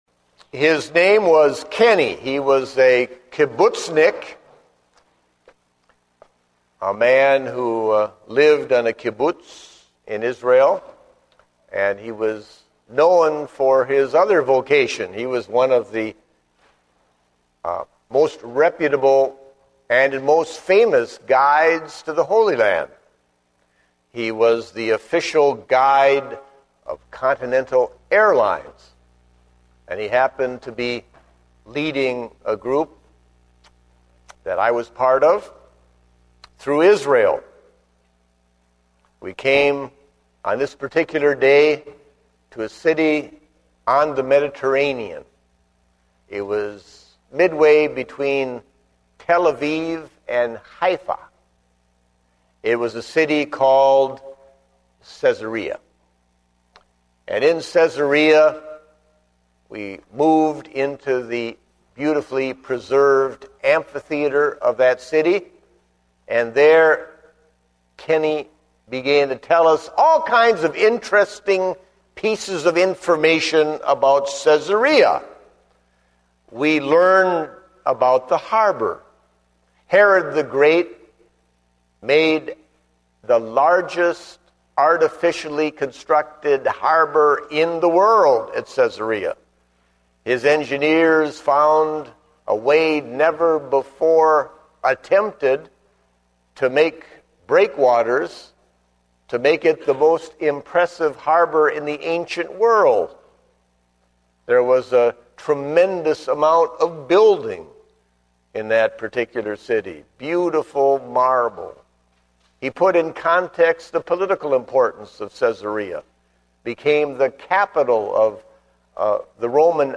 Date: August 1, 2010 (Morning Service)